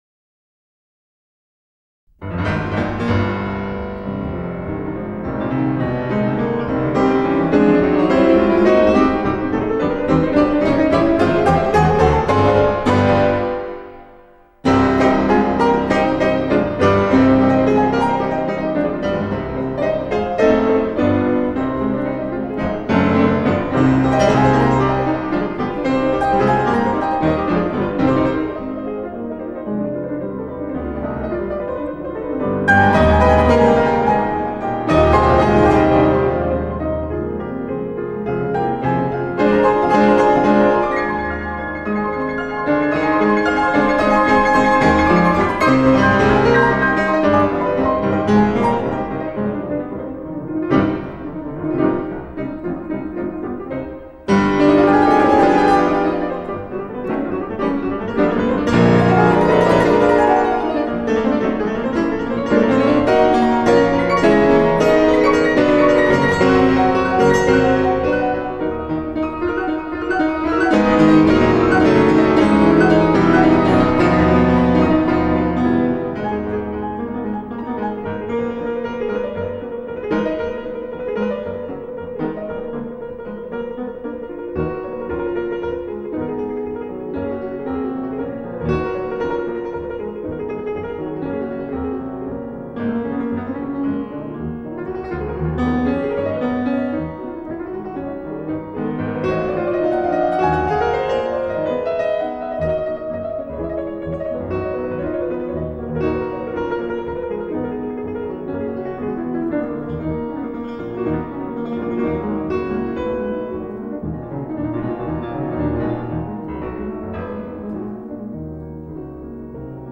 塔兰泰拉指的是被毒蜘蛛咬了以后用来解毒的狂舞,而这一首无疑是最燃炸的其中一首！